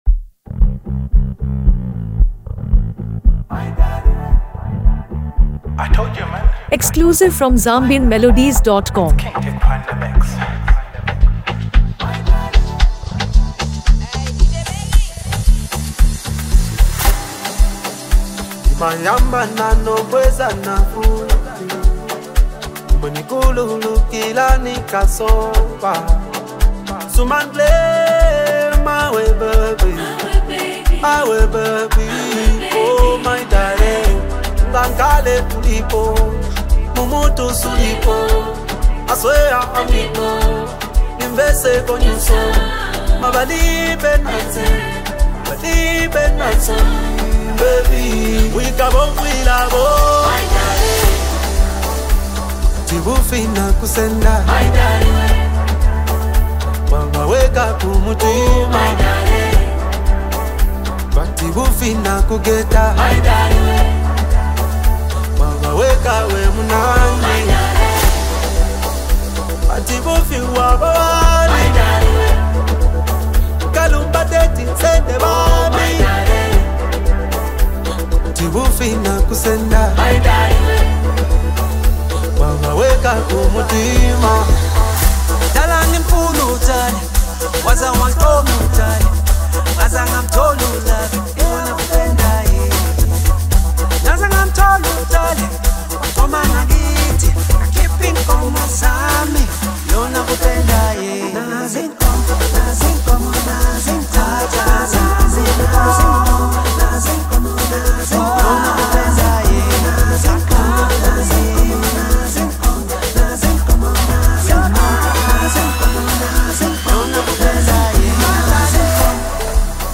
delivering a smooth Afro-pop love anthem.
Genre: Amapiano